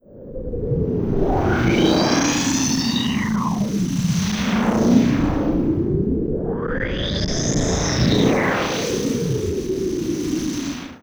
Space Jet.wav